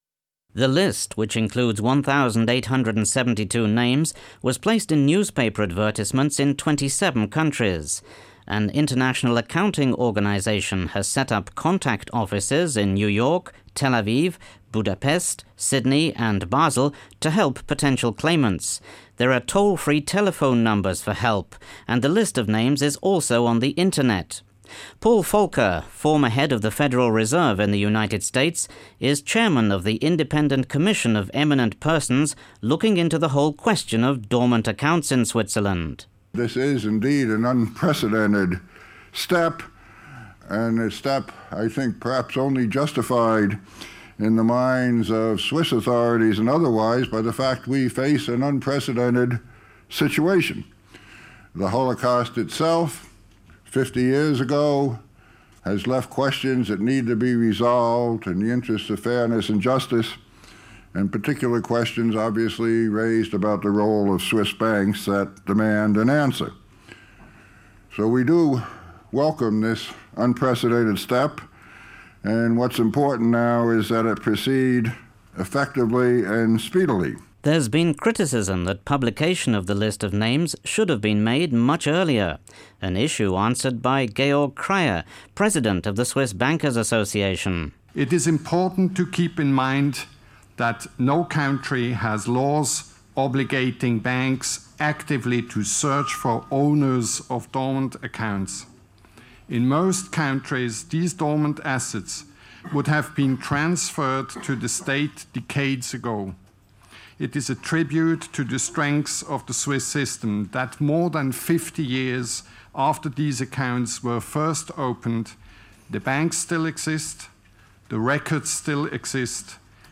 reports from a news conference given by the Swiss Bankers Association